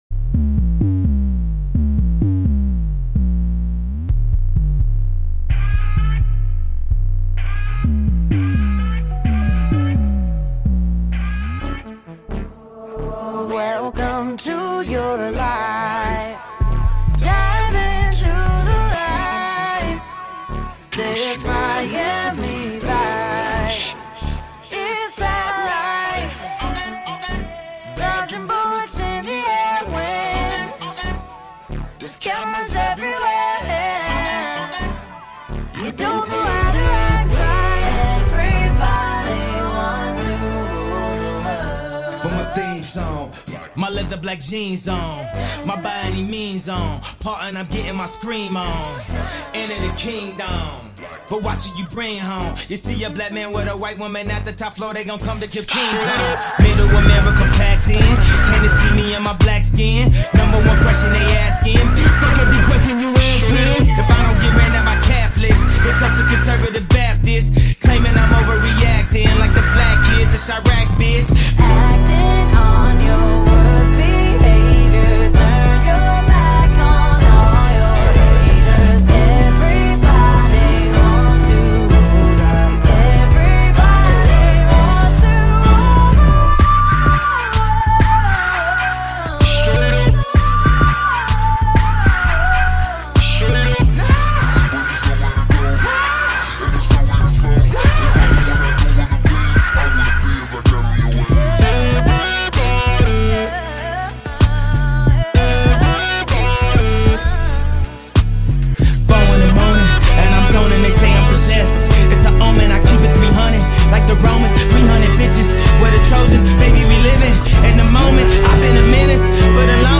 · by · in Hip Hop, Remix.
polished and perfected hip-hop with a pop twist.
blaring synths and a wandering back beat